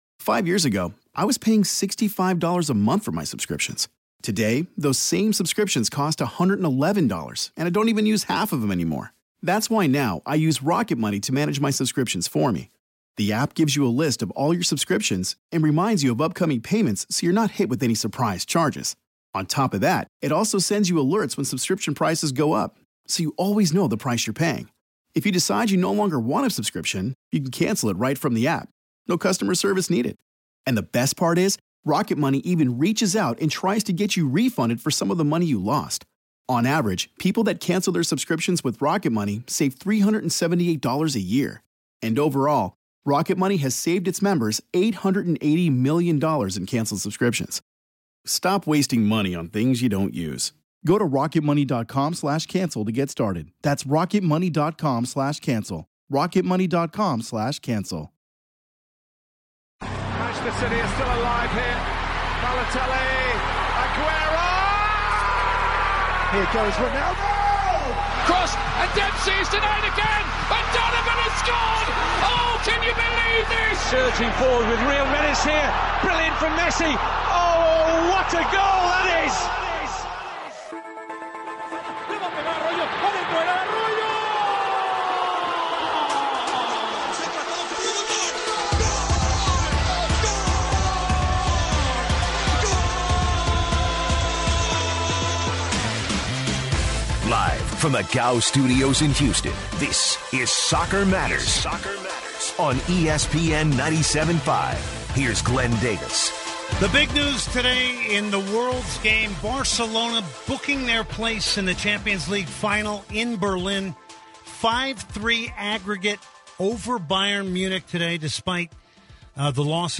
Callers talking Champions League and if Juventus can topple Real Madrid.